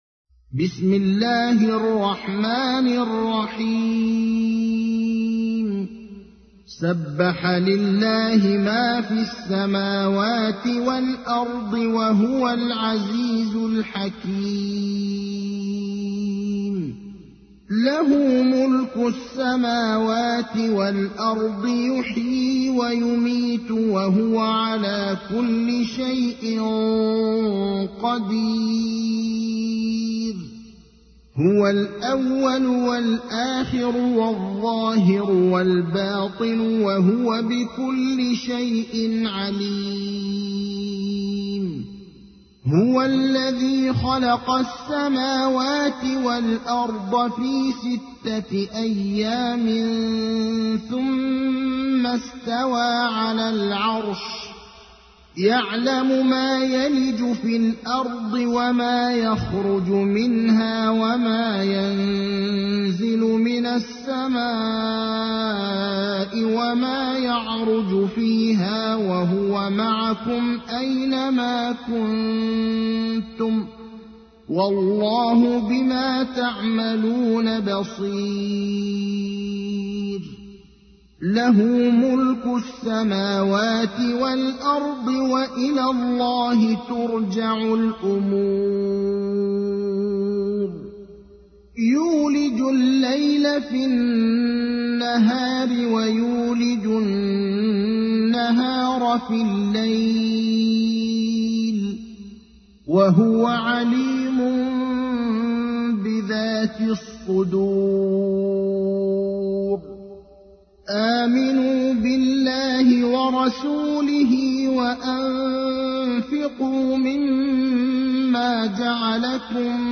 تحميل : 57. سورة الحديد / القارئ ابراهيم الأخضر / القرآن الكريم / موقع يا حسين